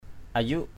/a-zuʔ/ (đg.) thổi = souffler jouer d’un instrument à vent. to blow. ayuk saranai ay~K sr=n thổi kèn Saranai = jouer de la saranai. ayuk tangi ay~K tz} thổi vào...